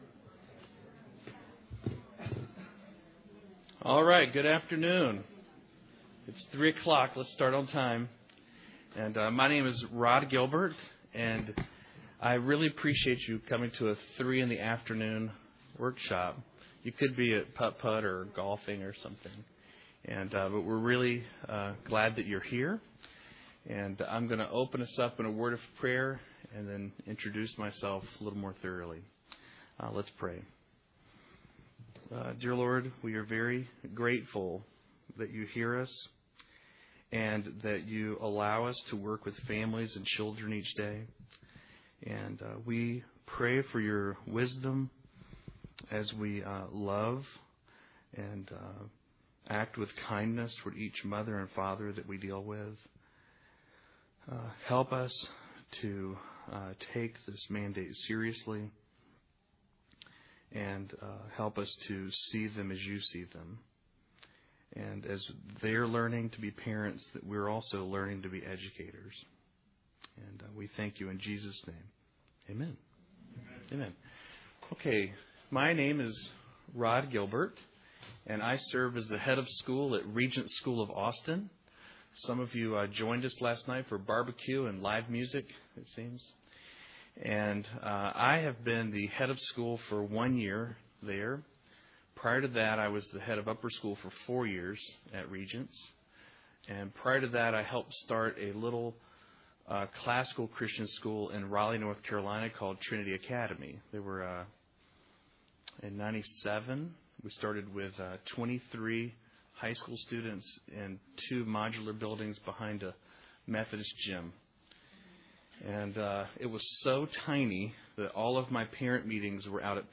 2008 Workshop Talk | 0:59:38 | All Grade Levels, Culture & Faith
Speaker Additional Materials The Association of Classical & Christian Schools presents Repairing the Ruins, the ACCS annual conference, copyright ACCS.